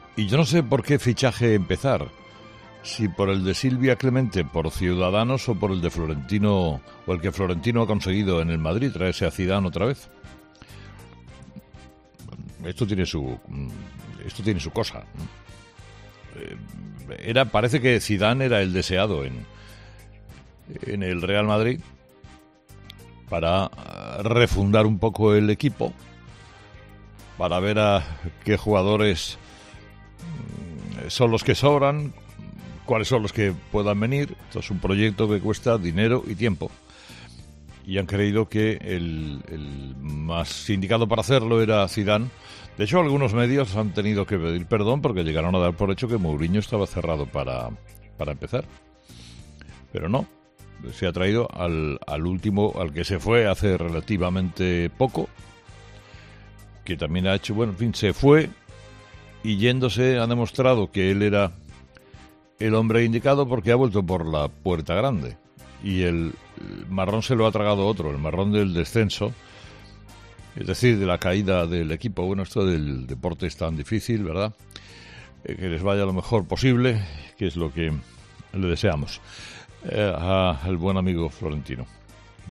Carlos Herrera ha comenzado el martes analizando la noticia del día: Zidane vuelve al banquillo del Real Madrid.
El comunicador comenzaba bromeando diciendo “No sé por que fichaje empezar. Si por el de Silvia Clemente por Ciudadanos o por el que Florentino ha conseguido para el Madrid con el fichaje de Zidane”.